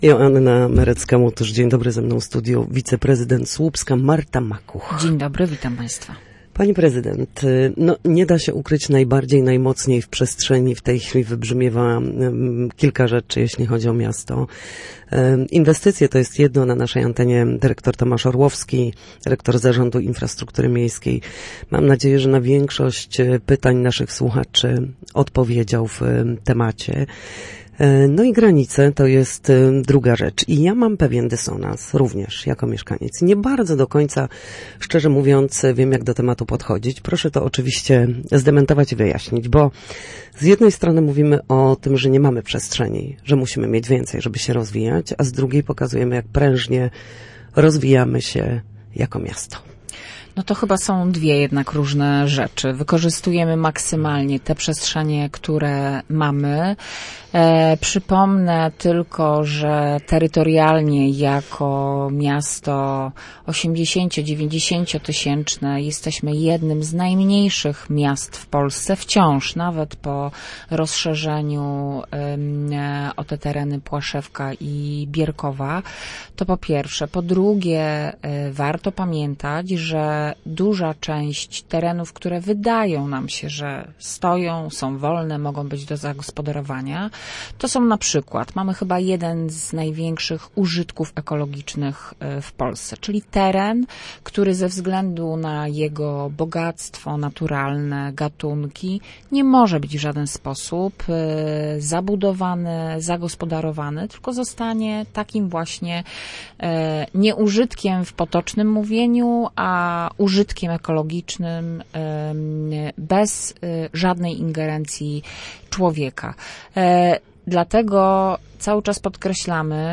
Porozumienie z gminami Kobylnica i Redzikowo jest konieczne dla rozwoju regionu słupskiego – uważa wiceprezydent Słupska Marta Makuch, która była gościem w Studiu Słupsk.